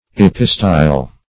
Search Result for " epistyle" : The Collaborative International Dictionary of English v.0.48: Epistyle \Ep"i*style\, n. [L. epistylium, Gr.